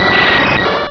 Cri de Nosferalto dans Pokémon Rubis et Saphir.